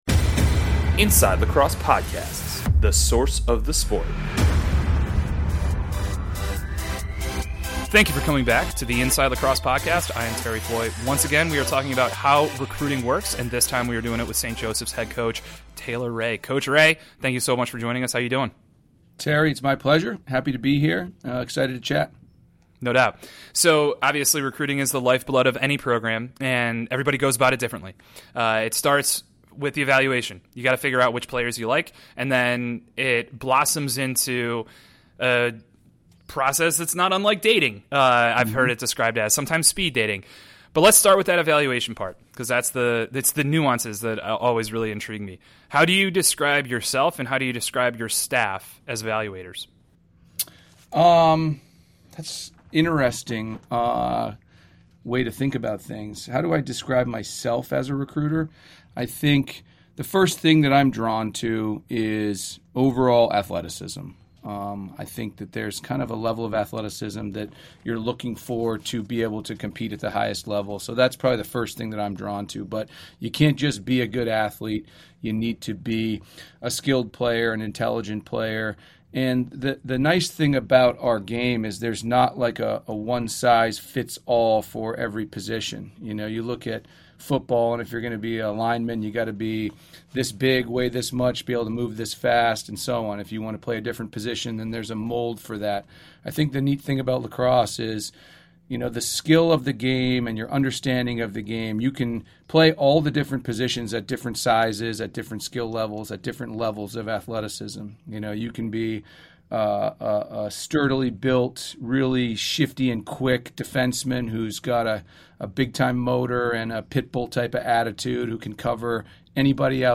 In an effort to round out that answer, he's interviewing a series of DI men's lacrosse coaches about their process